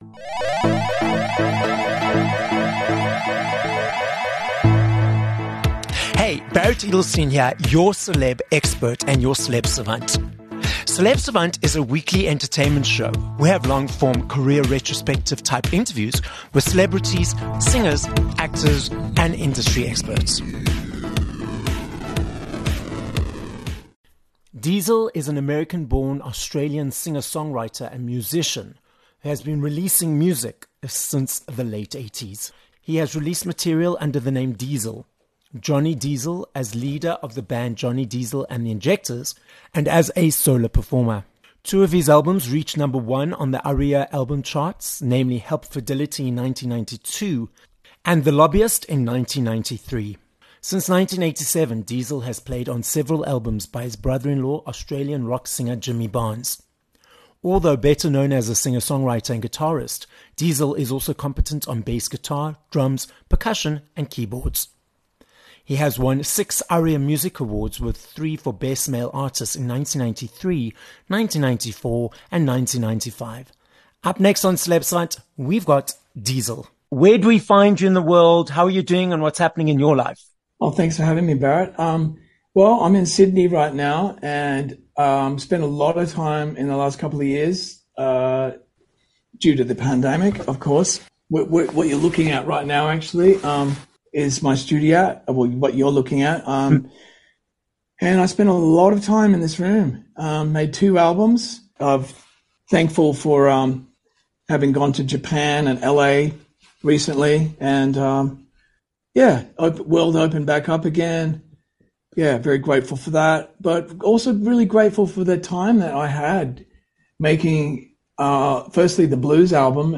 14 Dec Interview with Diesel
Multi Aria award-winning, American born, Australian based singer songwriter and musician, Diesel joins us on this episode of Celeb Savant. We hear where he gets his name from, and about his decades of success in the industry - performing as a solo artist, musician and part of the band Johnny Diesel and the Injectors.